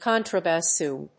/ˈkɑntrʌbæˈsun(米国英語), ˈkɑ:ntrʌbæˈsu:n(英国英語)/